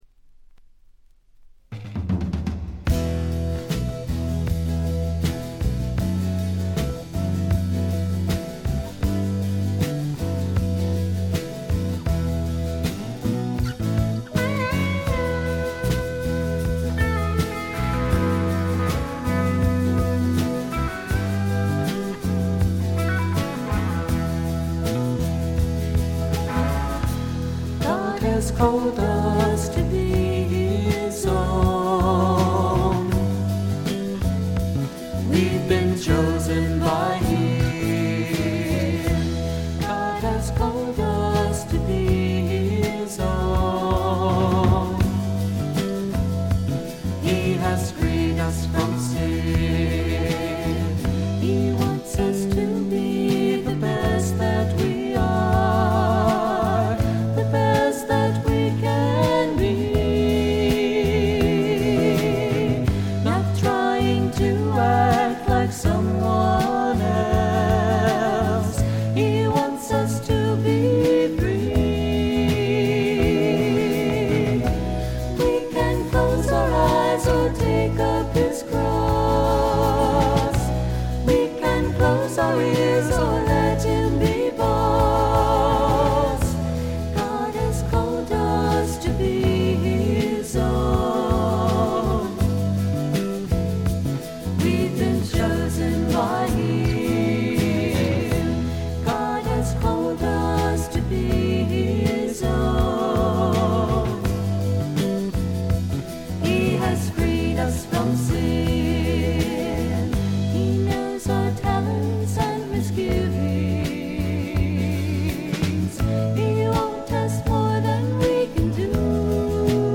ほとんどノイズ感無し。
全体に涼やかでメロウな味わいがたいへんに美味なもので、この音で好事家達が見逃すわけがありませんね。
試聴曲は現品からの取り込み音源です。